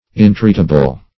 Search Result for " intreatable" : The Collaborative International Dictionary of English v.0.48: Intreatable \In*treat"a*ble\, a. [Pref. in- not + treatable.] Not to be entreated; inexorable.